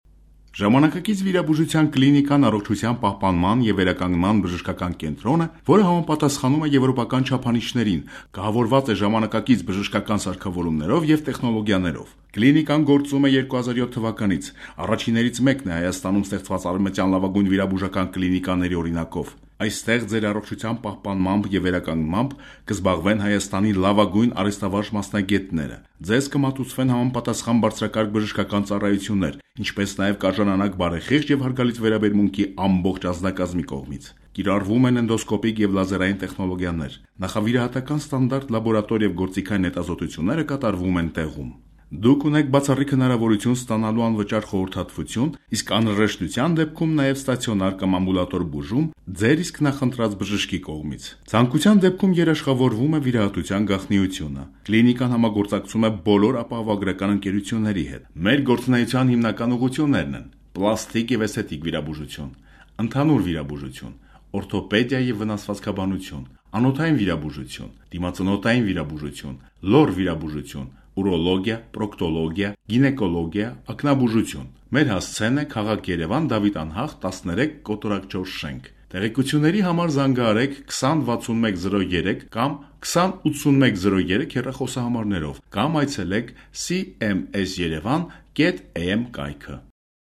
Armenian male voice, Armenian voice talent
Kein Dialekt
Sprechprobe: Werbung (Muttersprache):